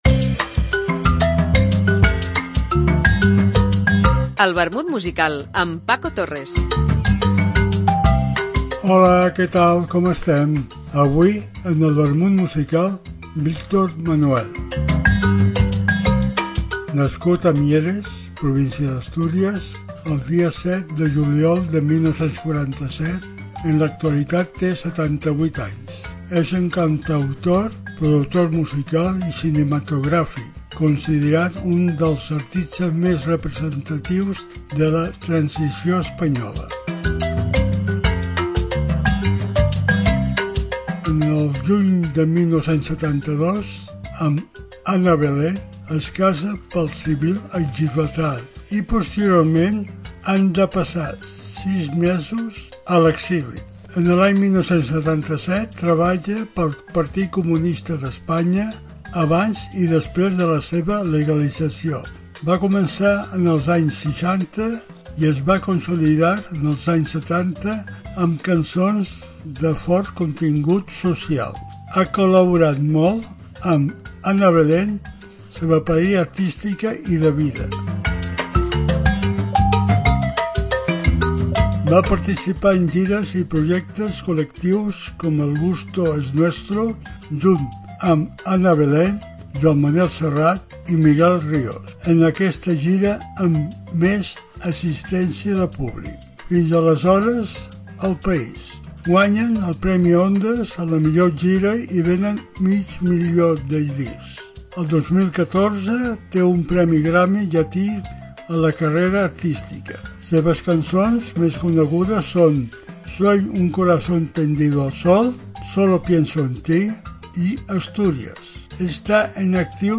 Una apunts biogràfics acompanyats per una cançó.